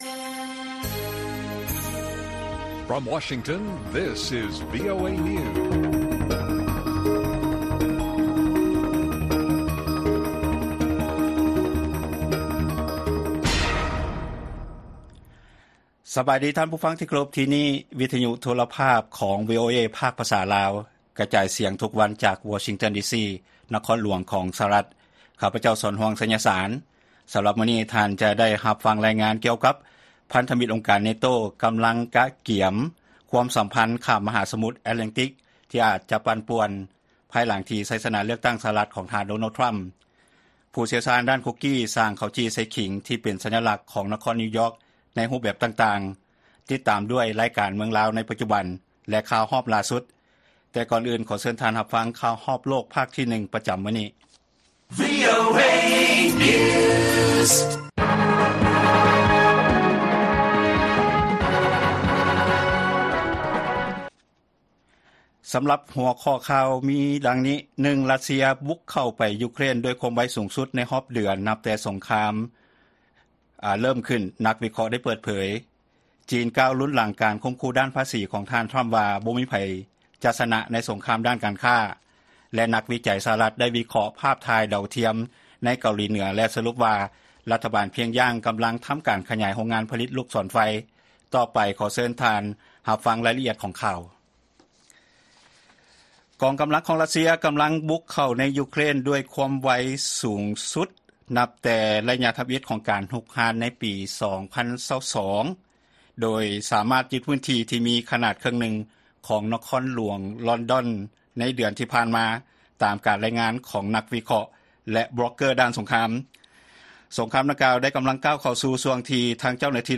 ລາຍການກະຈາຍສຽງຂອງວີໂອເອລາວ: ຣັດເຊຍ ບຸກເຂົ້າໃນ ຢູເຄຣນ ດ້ວຍຄວາມໄວສູງສຸດໃນຮອບເດືອນ ນັບຕັ້ງແຕ່ສົງຄາມເລີ້ມຕົ້ນຂຶ້ນ